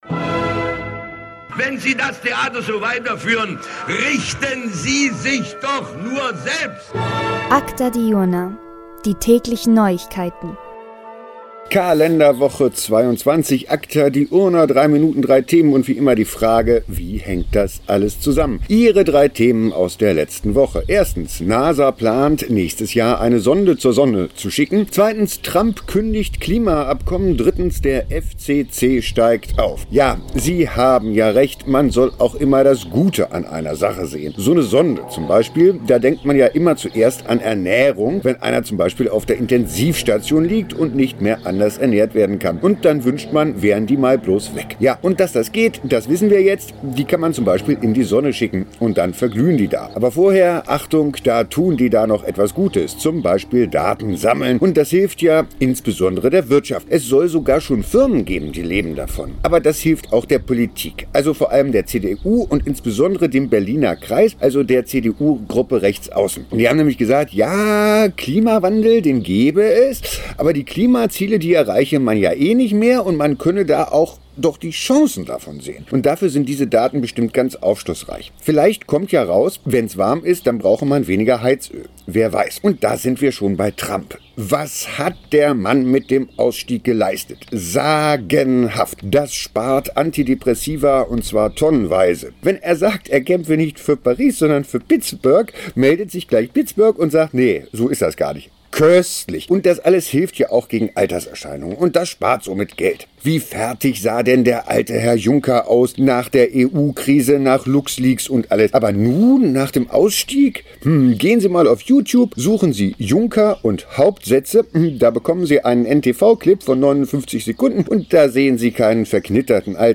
Diesmal direkt aus dem Garten!